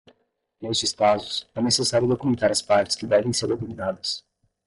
Pronounced as (IPA)
/do.ku.mẽˈta(ʁ)/